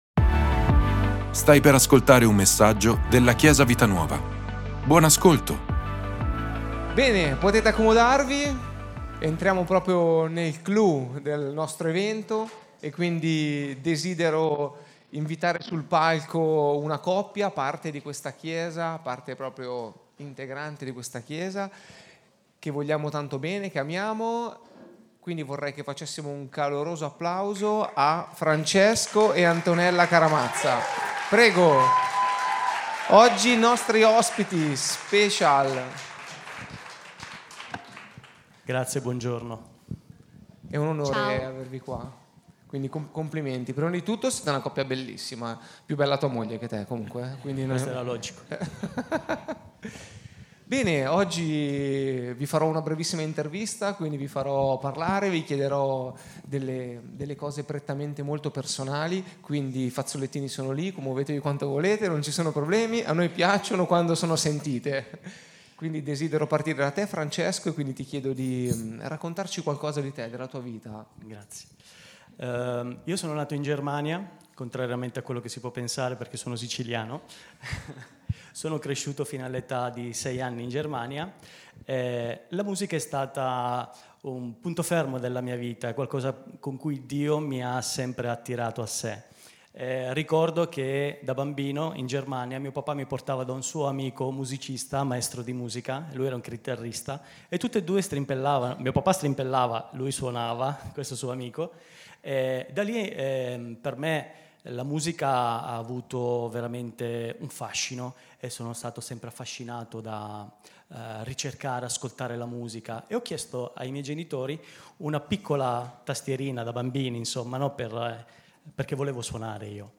Ascolta la predicazione: Chi è Gesù per te? - Chiesa Vita Nuova